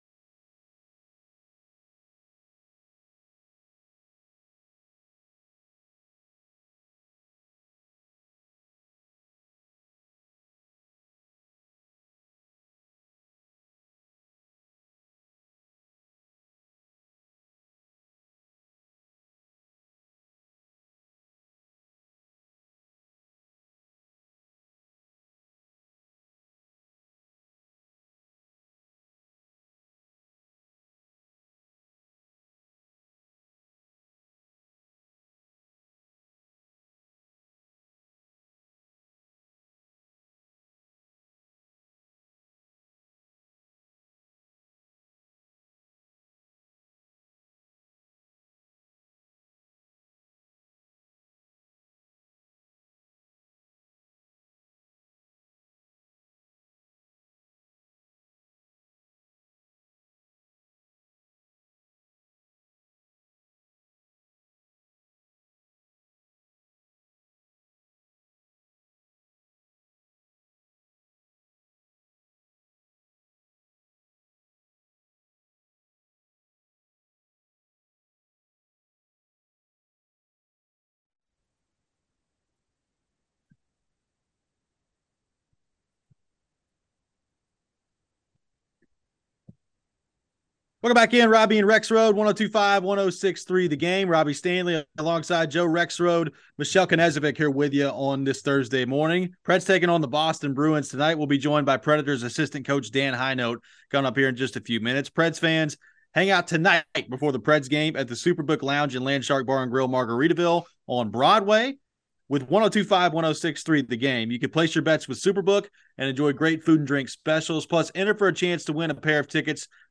Preds Assistant Dan Hinote Interview